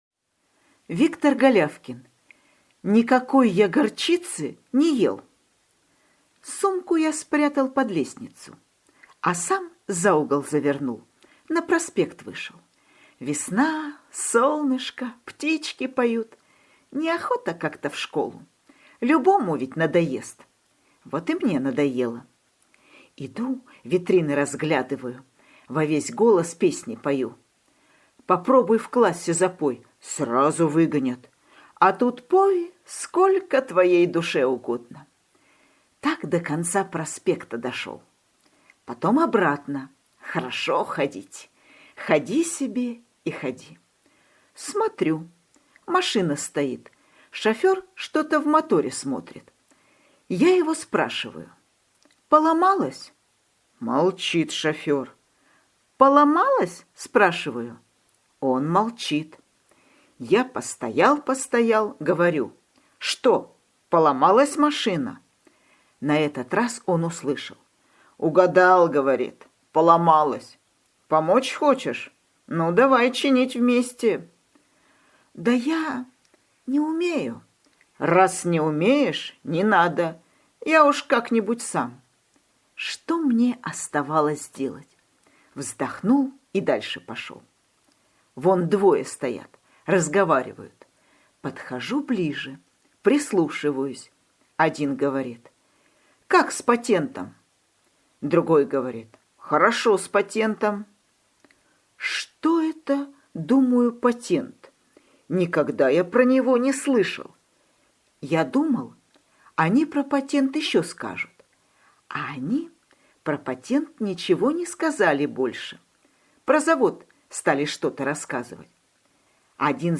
Аудиорассказ «Никакой я горчицы не ел»
Очень хорошо и выражение отличное